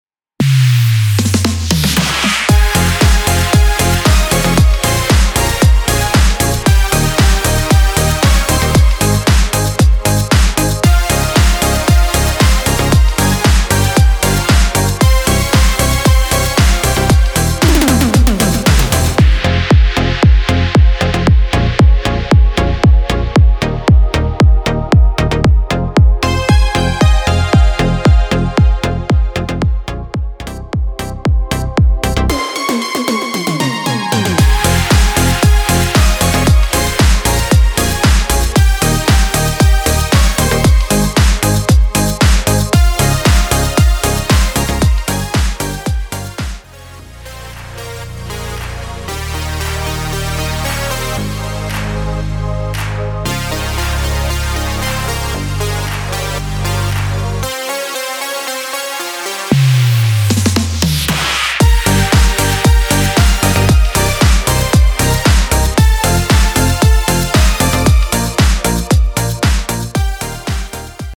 Tonacija: F-Eb-G